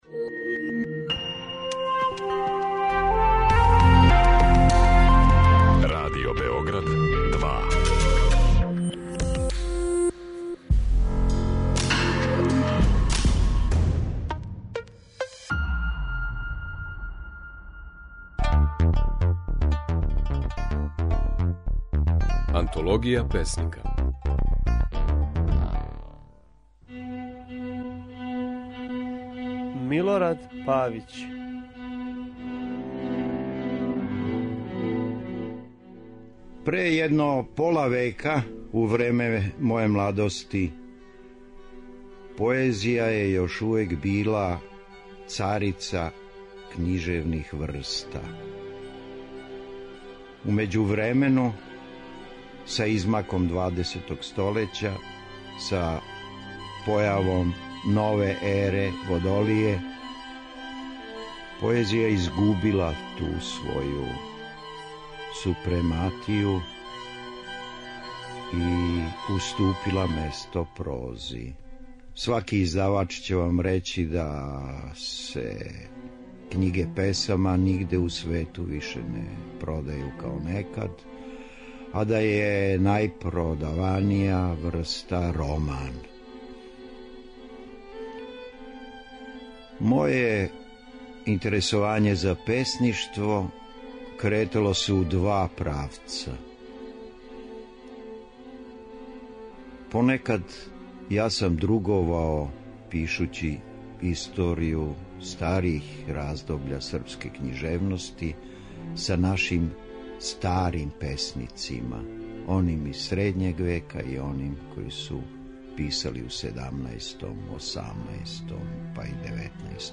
Kако је своје стихове говорио Милорад Павић
Можете слушати како је своје стихове говорио романсијер, приповедач, драмски писац, али и песник - Милорад Павић (1929-2009).